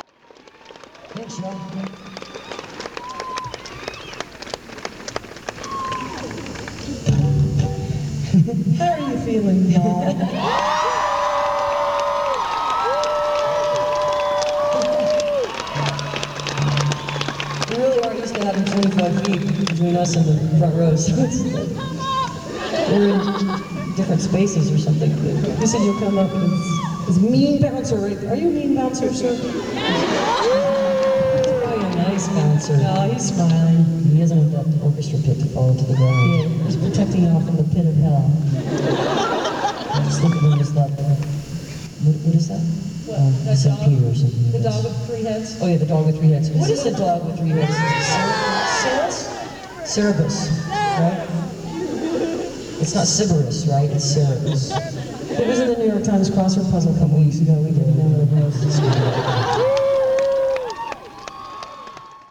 06. talking with the crowd (1:02)